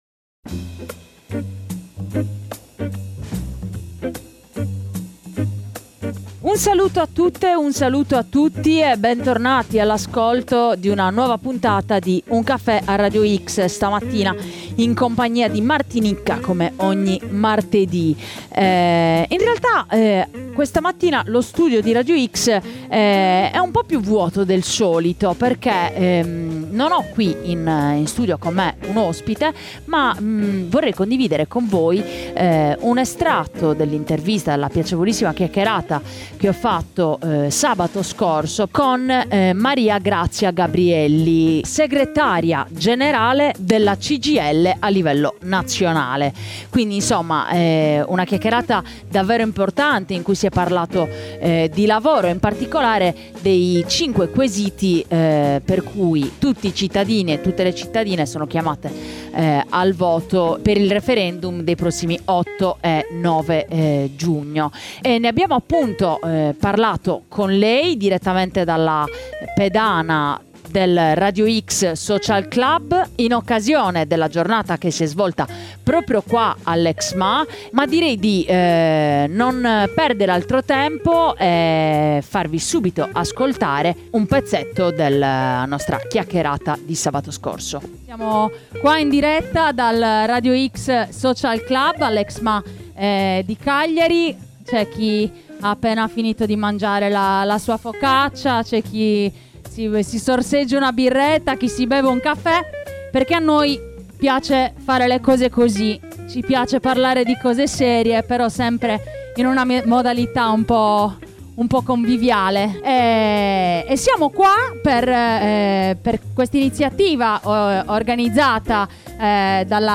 è intervenuta ai microfoni di Un caffè a Radio X per raccontare le ragioni del referendum. L’obiettivo è chiaro: rimettere al centro i diritti, a partire dal lavoro.